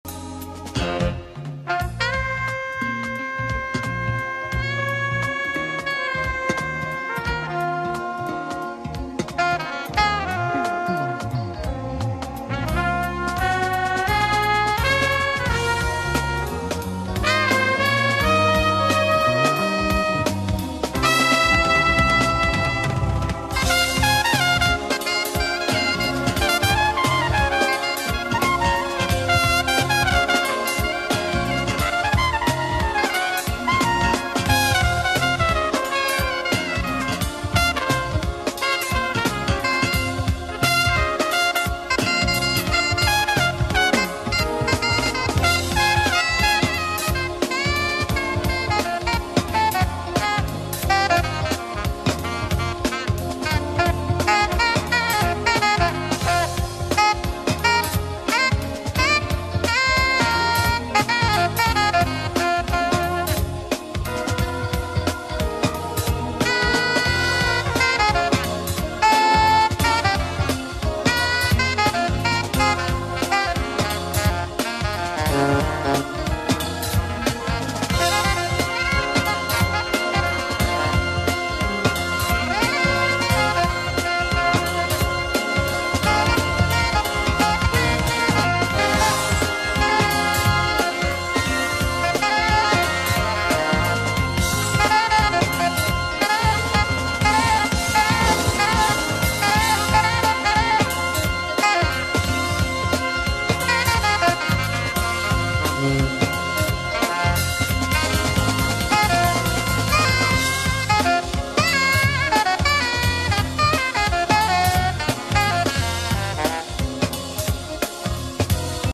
jazz musician
in Hamburg, 1991